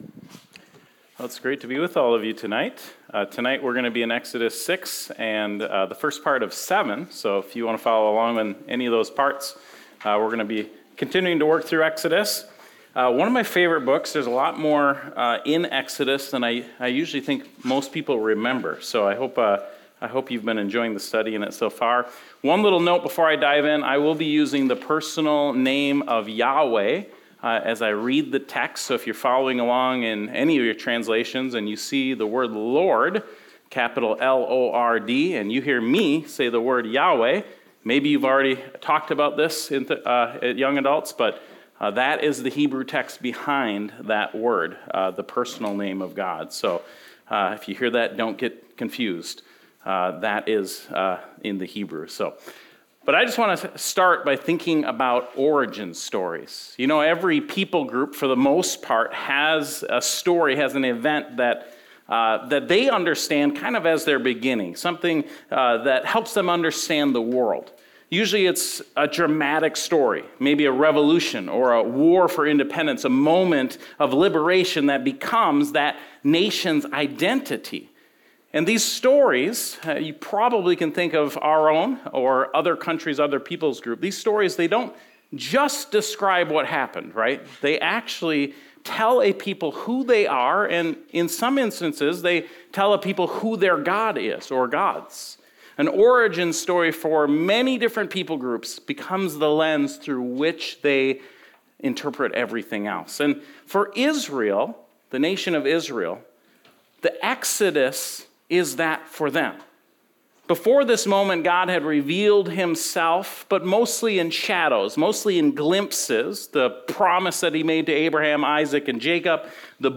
Highland Community Church sermons